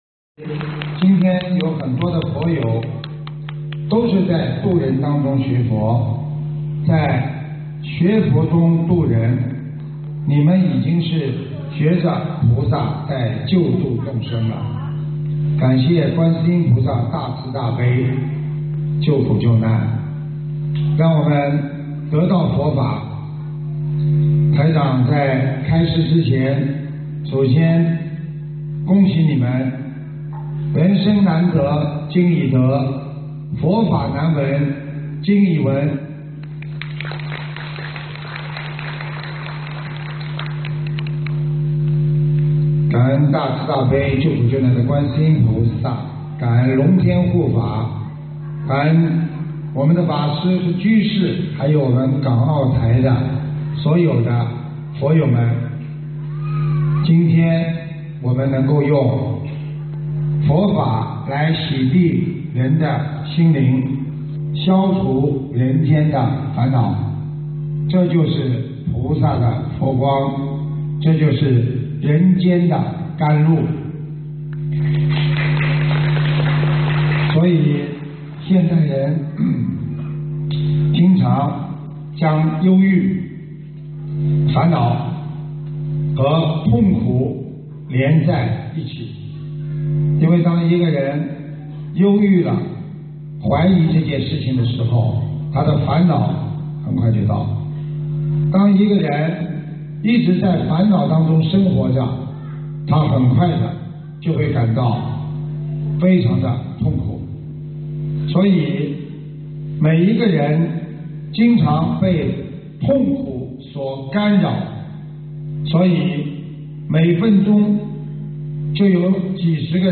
中国香港、澳门、台湾视频联线开示151108